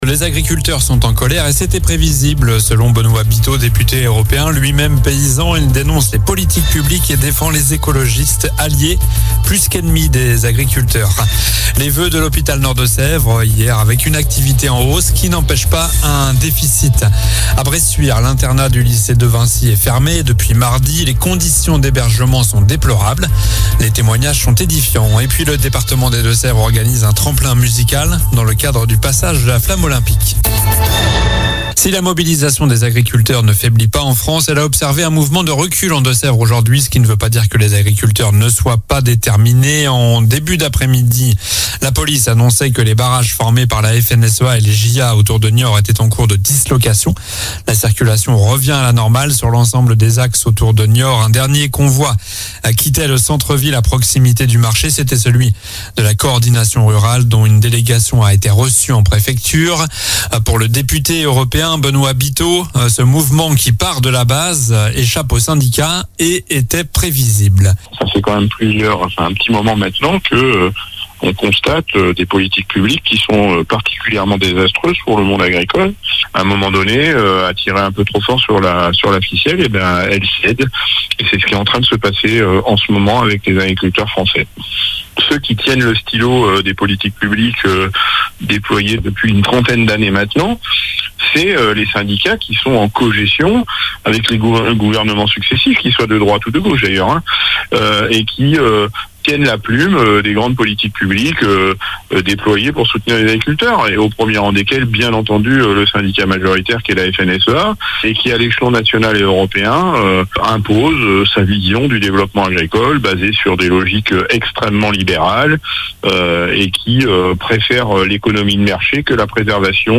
JOURNAL DU JEUDI 25 JANVIER ( soir )